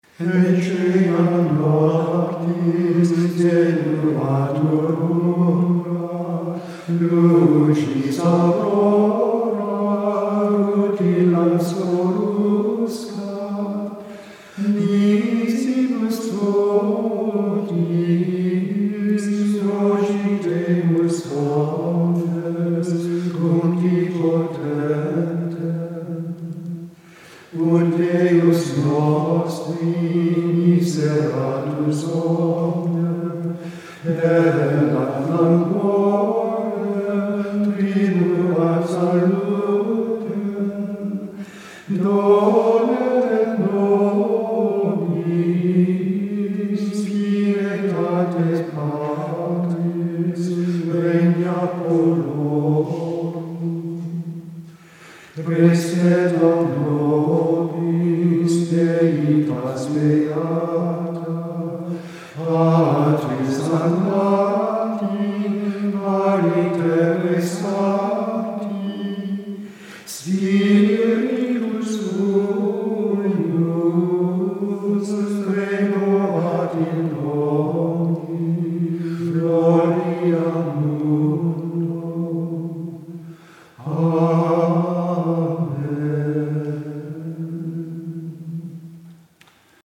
Gregorianische Gesänge – aus der jahrhundertealten Tradition des Stundengebets der Mönche – sind Gesänge nach Zisterzienser Singweise zur feierlichen ersten Vesper für das Fest Maria Geburt.
Gregorianische Gesänge
Digitale Aufnahme
Laudes – Gesang